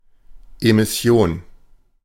Ääntäminen
IPA : /lɔːntʃ/